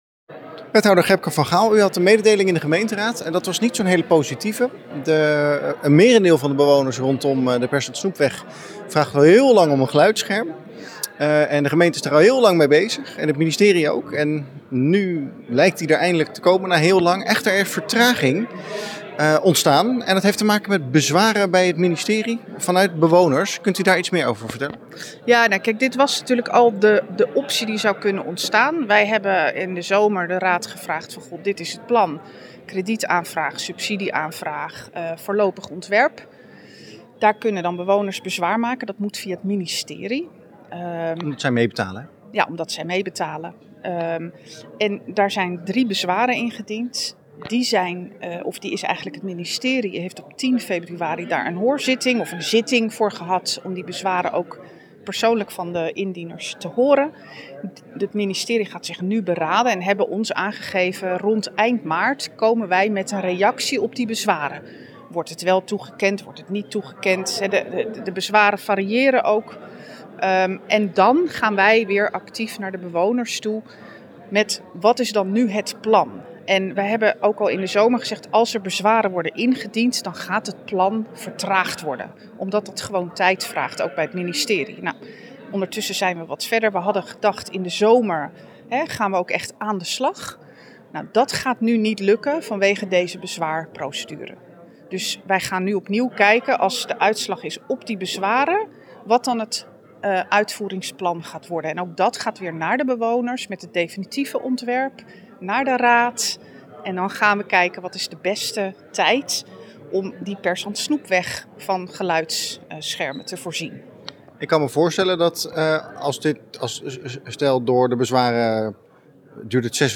in gesprek met wethouder Gebke van Gaal.